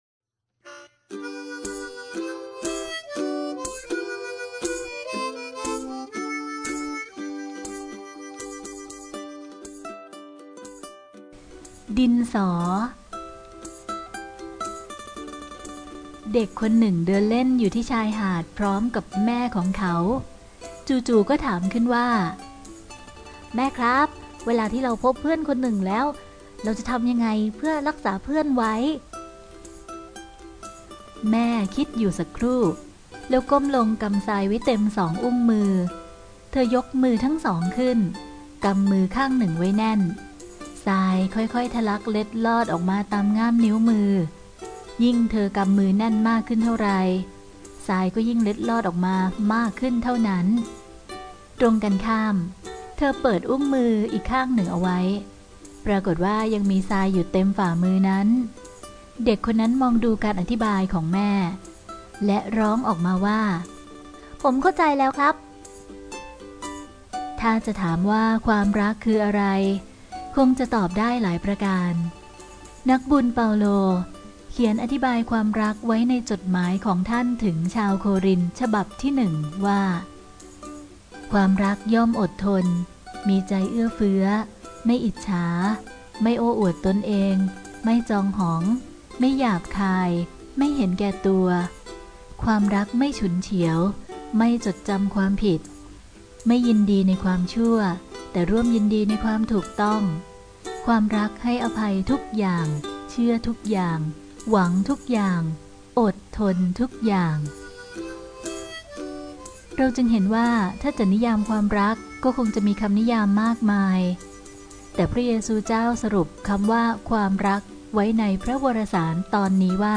เสียงอ่านโดย